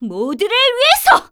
cleric_f_voc_skill_justicecrash.wav